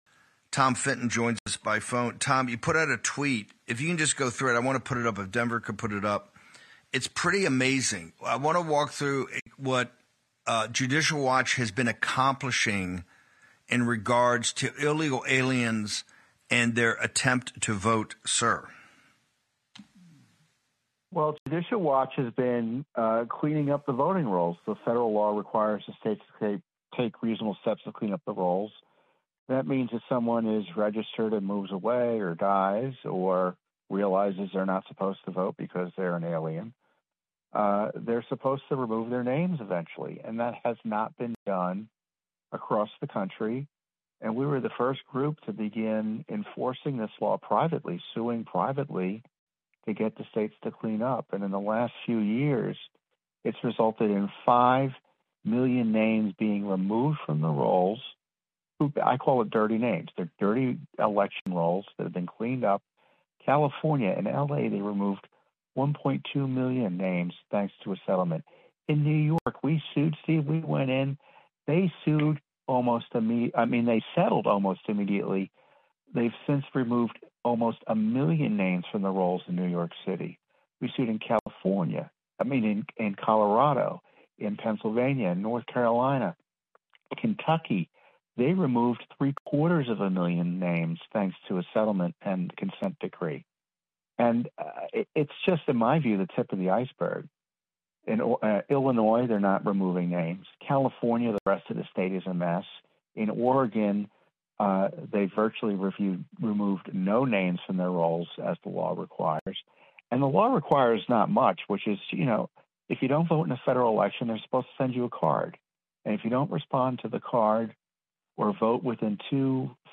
Transcript Tom Fenton joins us by phone.